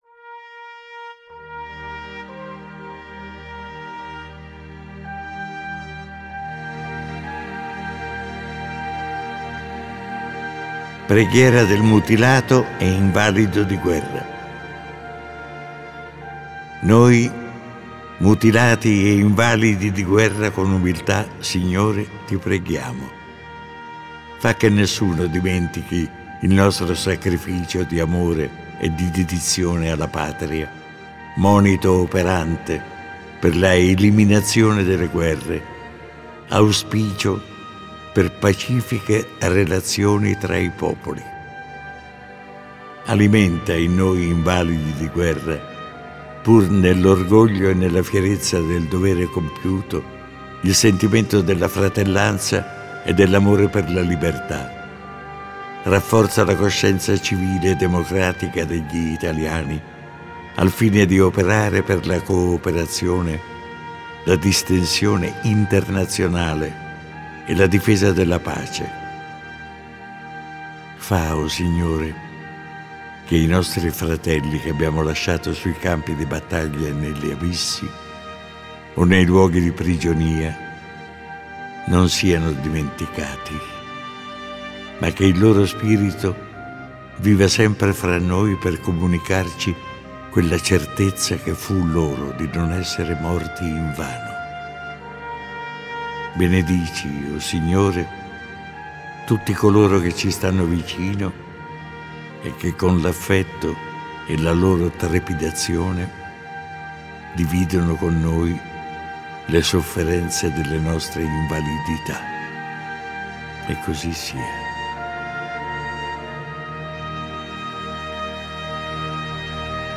Preghiera-del-Mutilato-e-Invalido-di-guerra-Arnoldo-Foa.mp3